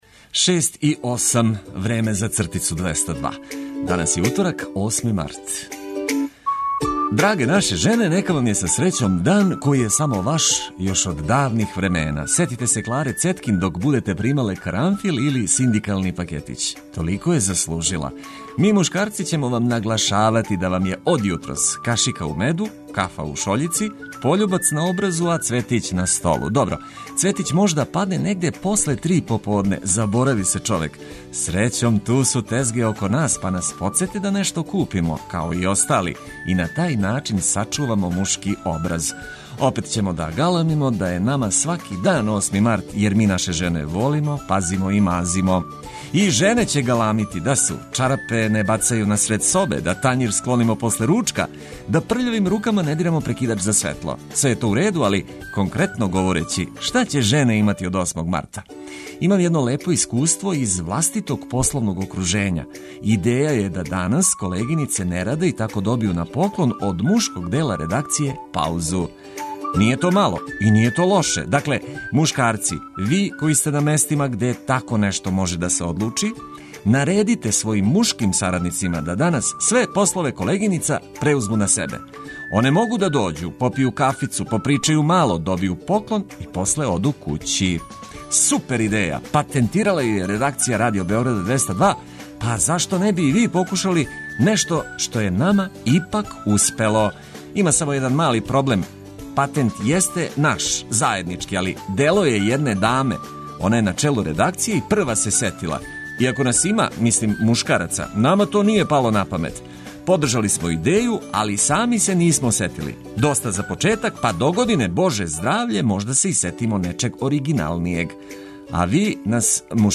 Поплаве у Србији. Где је најкритичније, извештај са терена, репортери и представници МУП-а, сектор за ванредне ситуације.